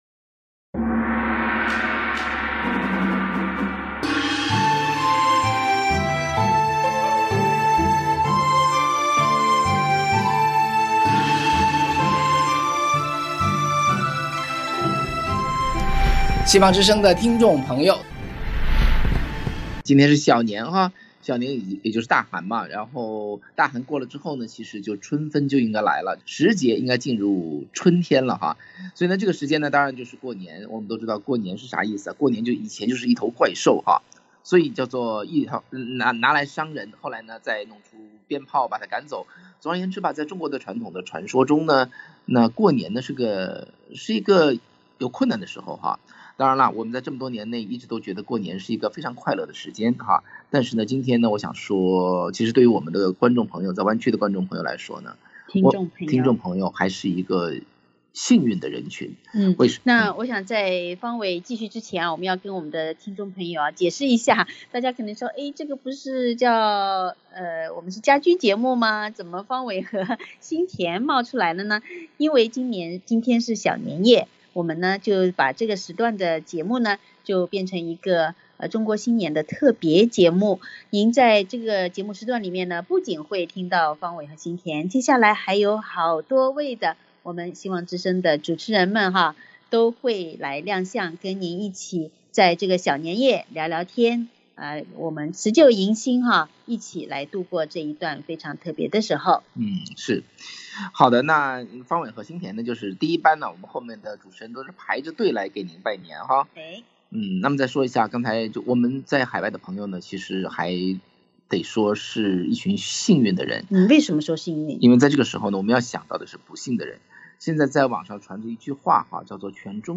新年特別節目：2023中國新年主持人給聽眾拜年